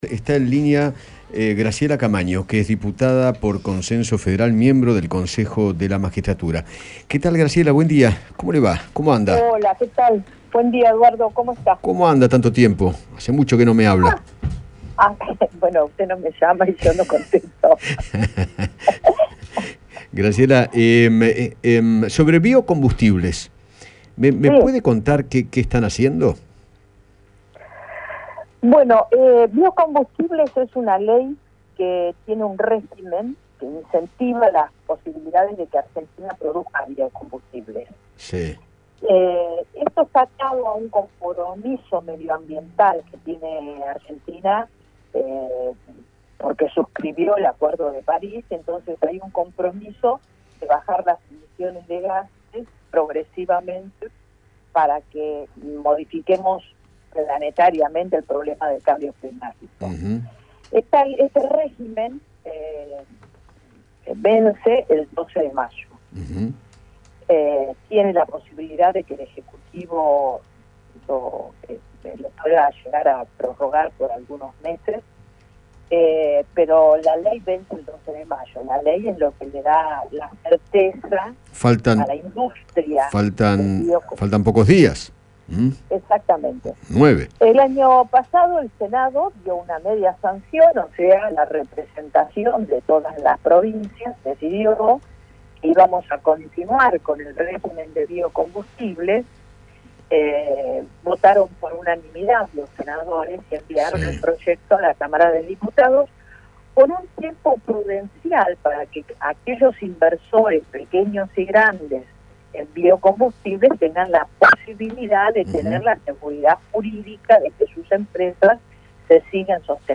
Graciela Camaño, diputada nacional, habló con Eduardo Feinmann sobre la ley de Biocombustibles que propone un nuevo régimen hasta el 2030.